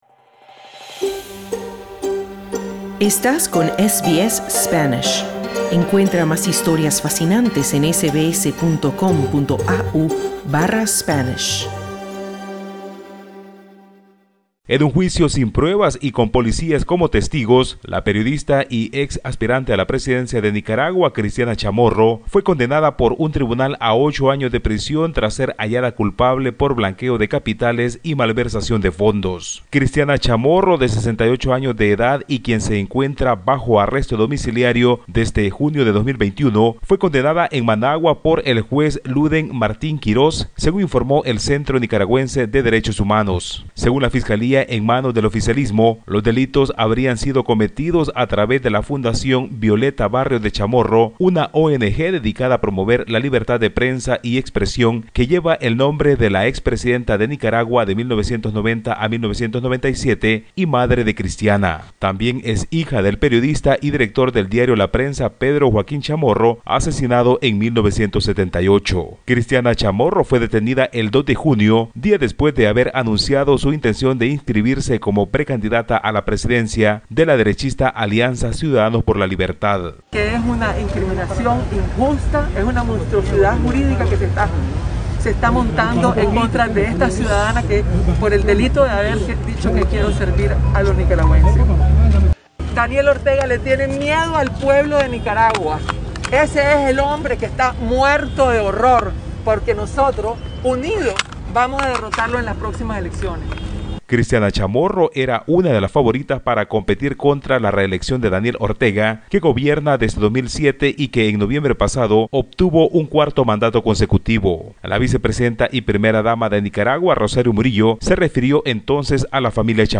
La opositora y exaspirante a la Presidencia de Nicaragua, Cristiana Chamorro, fue condenada a ocho años de prisión por delitos imputados por el gobierno de Daniel Ortega, que le impidieron competir en 2021 contra la reelección del excomandante guerrillero. Escucha el informe del corresponsal de SBS Spanish en Latinoamérica